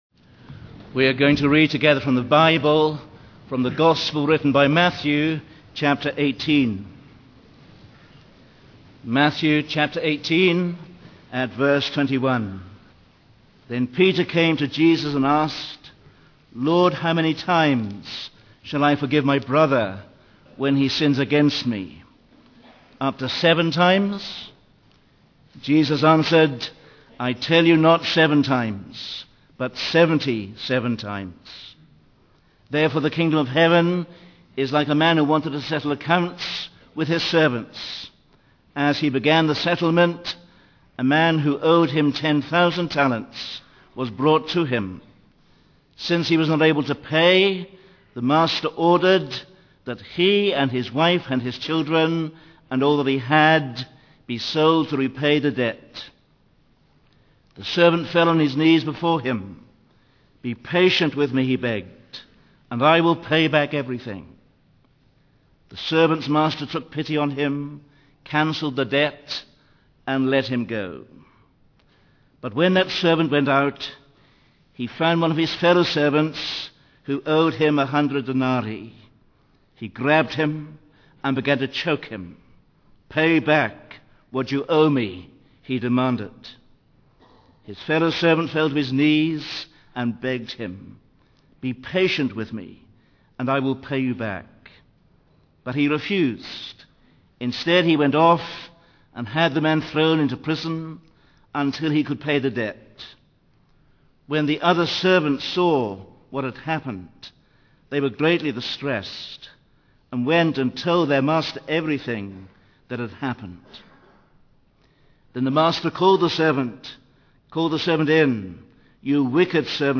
In this sermon, the speaker begins by recounting a parable from the Bible about a servant who owed a debt to his master.